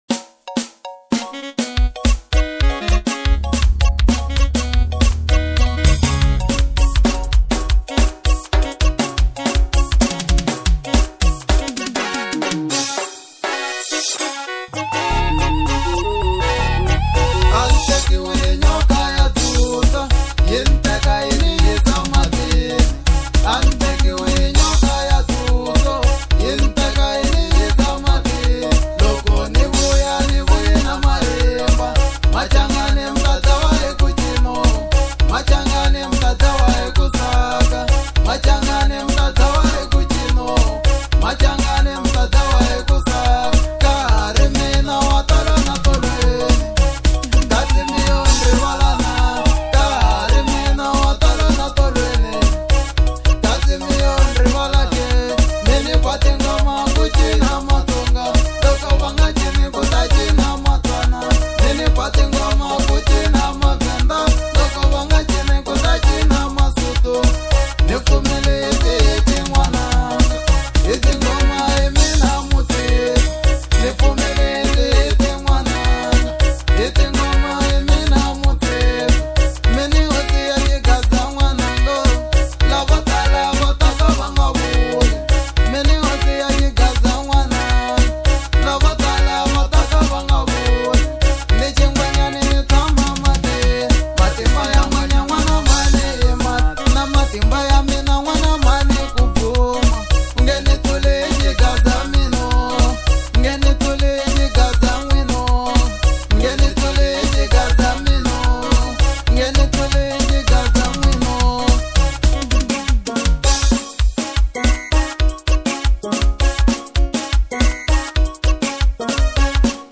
04:14 Genre : Xitsonga Size